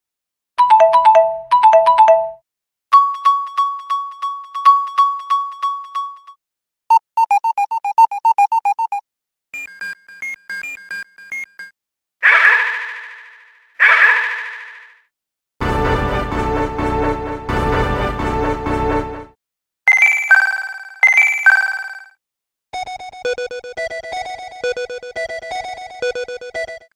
Play with the pads to make a quick loop with the built-in sample library, which you can extend by recording with iPhone's microphone or bouncing the working sequence.
SOUND DEMO 01 "Presets"